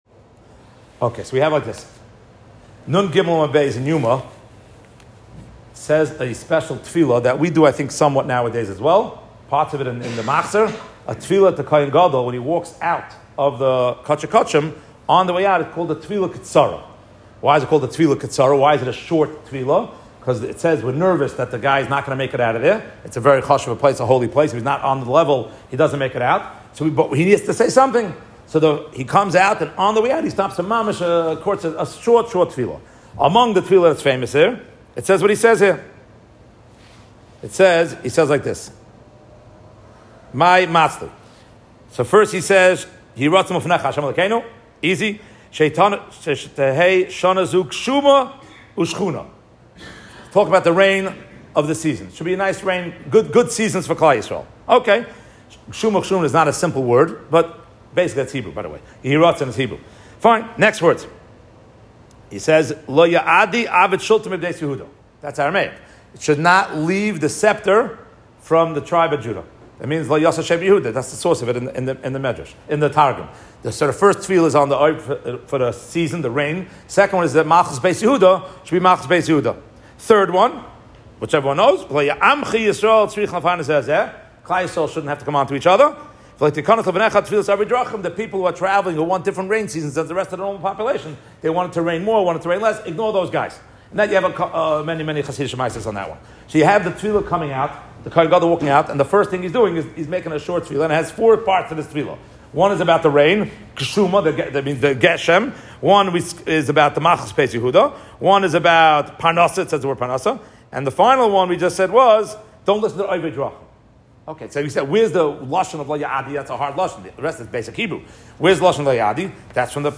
Shaarei Halacha, Monsey.